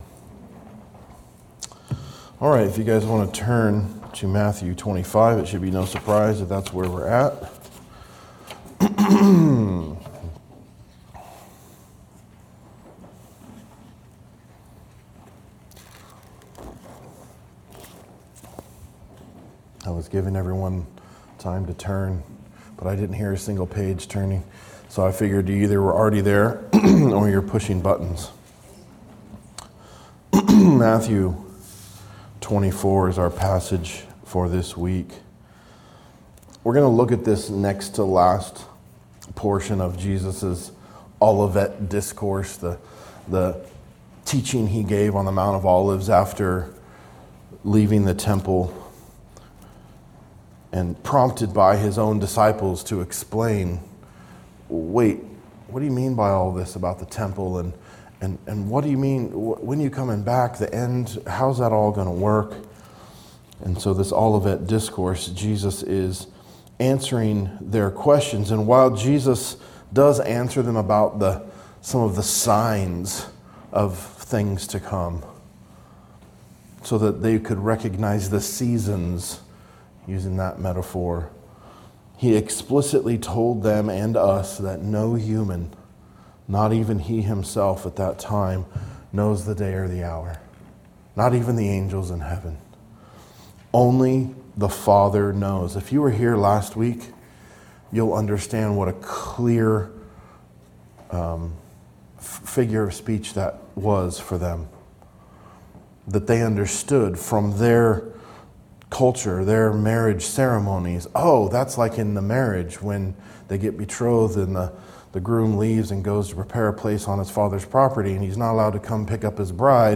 Verse by verse exposition of Matthew's Gospel